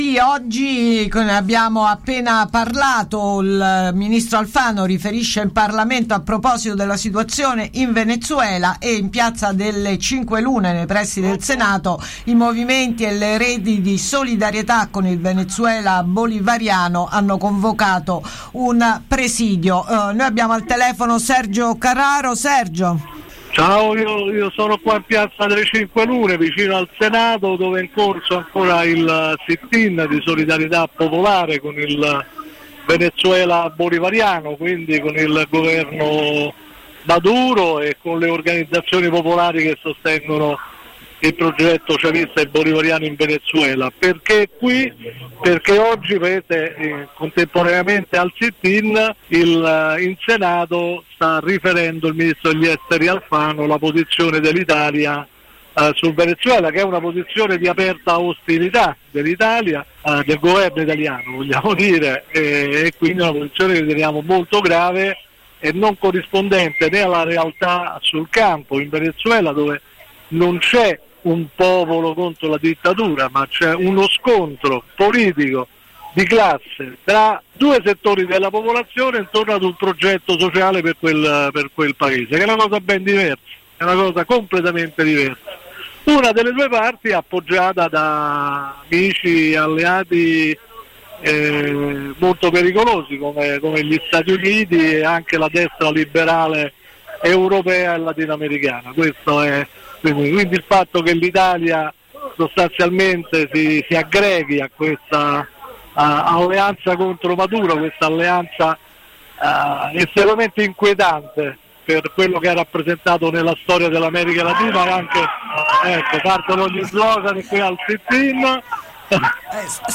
Solidarietà con il Venezuela Bolivariano – intervista